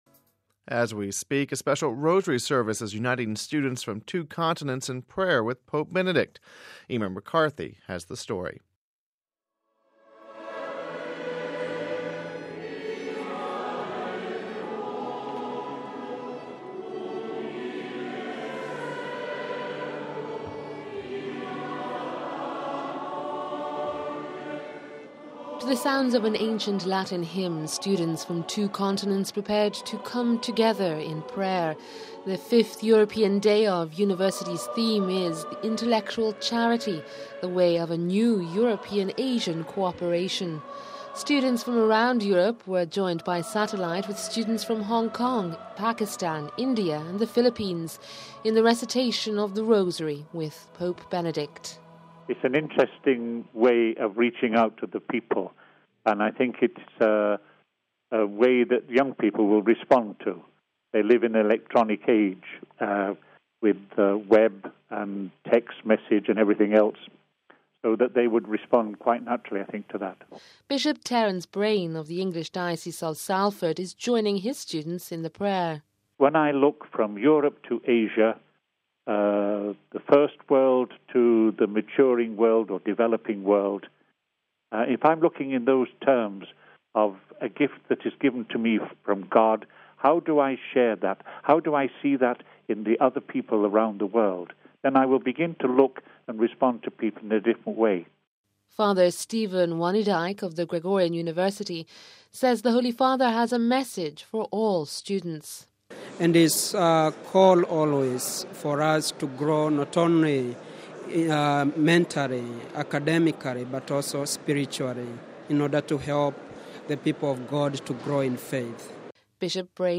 Home Archivio 2007-03-10 18:34:35 Pope Benedict Recites Rosary With Students (10 March 07 - RV) Pope Benedict XVI met with students from Europe and Asia via satellite to recite the rosary on Saturday evening. We have this report...